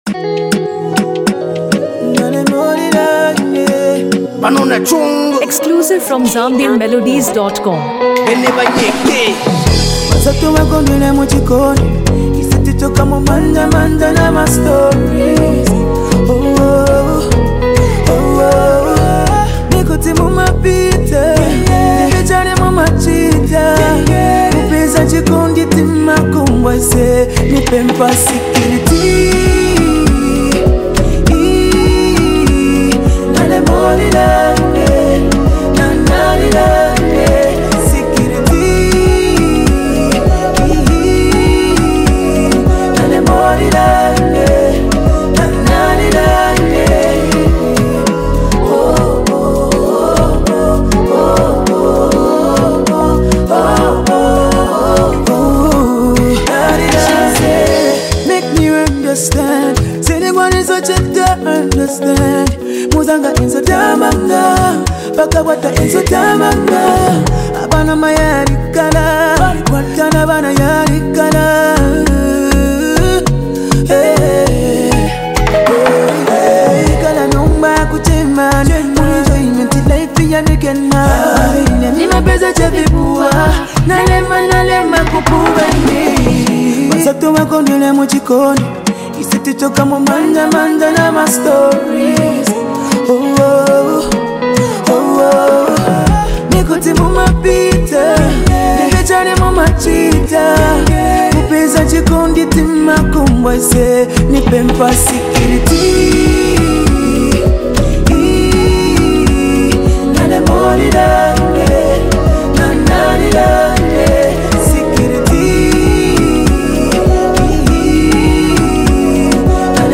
powerful verses bring raw street authenticity